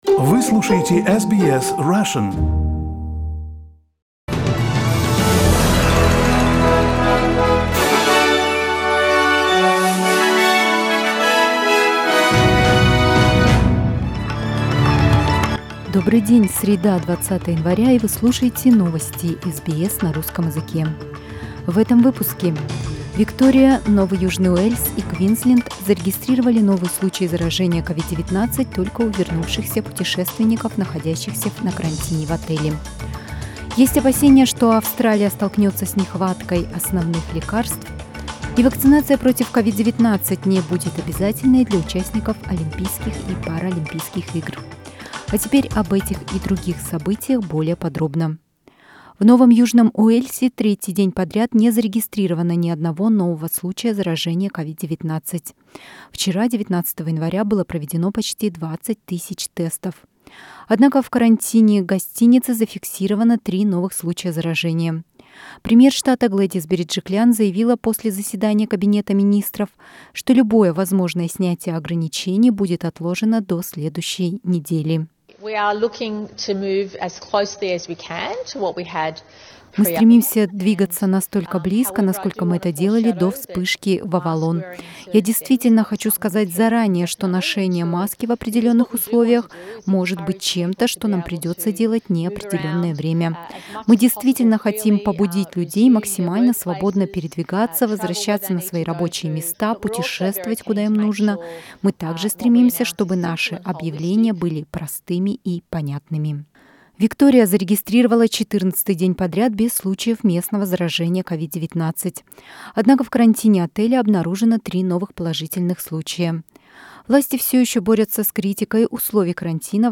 News Bulletin in Russian, January 20